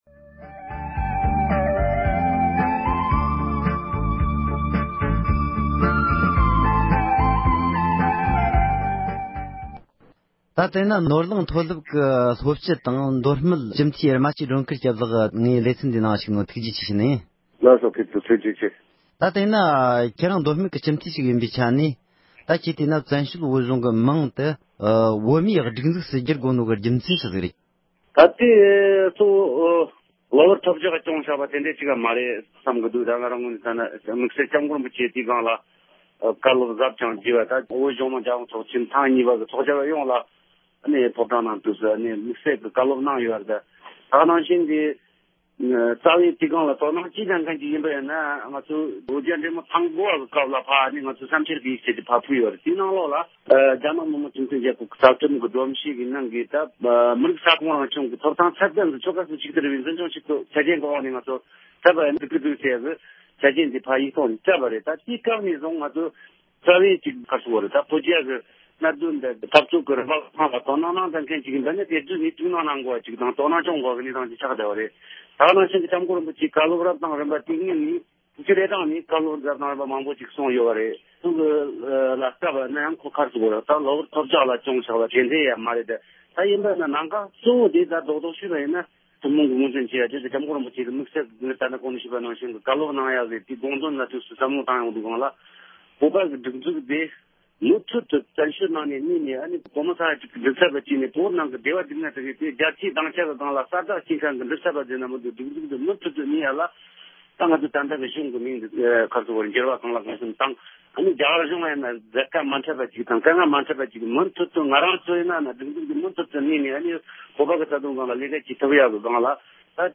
སྒྲ་ལྡན་གསར་འགྱུར།
༄༅༎དེ་རིང་གི་རྩོམ་རིགས་ཀྱི་དོ་ར་ཞེས་པའི་ལེ་ཚན་ནང་དུ། བཙན་བྱོལ་བོད་གཞུང་གི་མིང་དེ་བོད་མིའི་སྒྲིག་འཛུགས་སུ་བསྒྱུར་ཡོད་པའི་སྐོར། ཀེ་ཐོས་མདོ་སྨད་སྤྱི་འཐུས་རྨ་ཆུའི་སྒྲོལ་དཀར་སྐྱབས་ལགས་སུ་བཅར་འདྲི་ཞུས་པ་ཞིག་ལ་གསན་རོགས་ཞུ༎